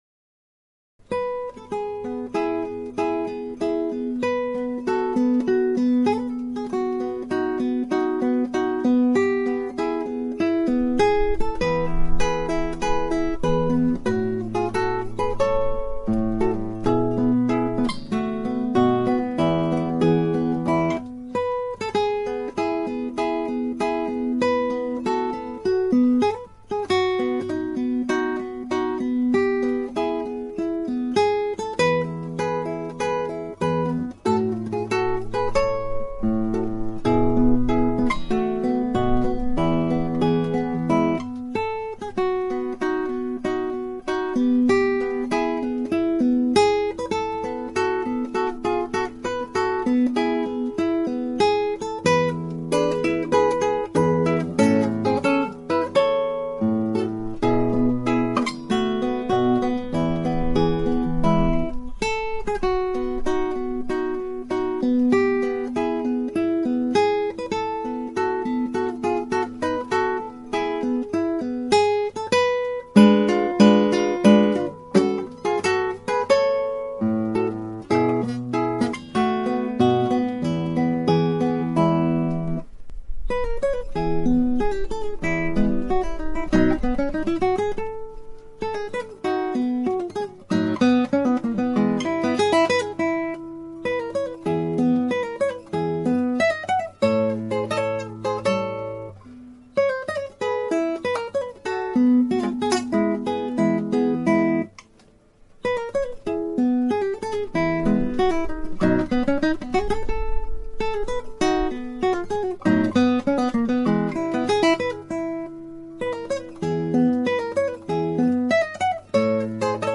(アマチュアのクラシックギター演奏です [Guitar amatuer play] )
相変わらず私の演奏はゆっくりですがコーダに入って非常にゆっくりになってしまいました。アルペジオ的な所で疲れもあって動かなくなり遅く弾くしかありませんでした。またあちこち指がまわっておりません。
後半の32分音符4つの繰り返しは弾弦は軽くですが強・弱・強・弱・強・弱・強と表現してみました。
9小節と11小節の高音のBの4つ連続とそのエコーはパパゲーノの笛のように私は感じたのでそれを録音のように弾いてみました。
この変奏はテンポを崩さないことと低音の消音に気を付けています。